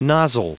Prononciation du mot nozzle en anglais (fichier audio)
Prononciation du mot : nozzle